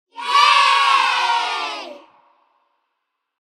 Kids-cheering-sound-effect.mp3